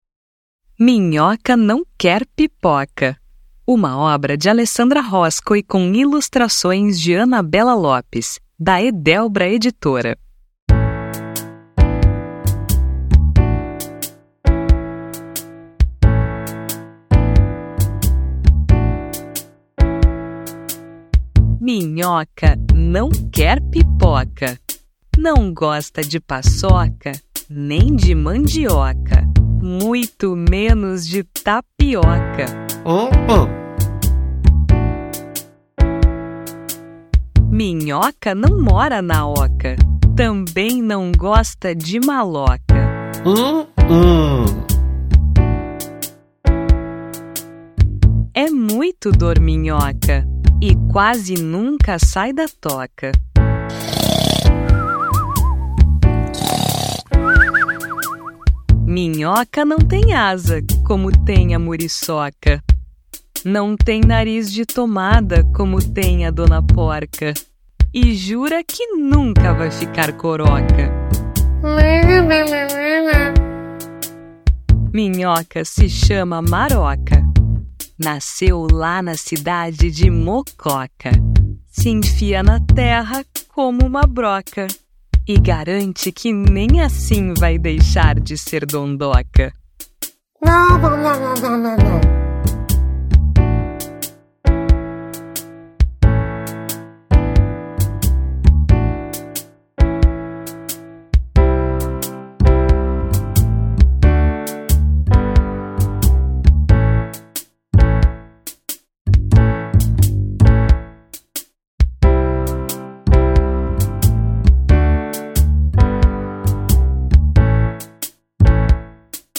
Amostra do audiolivro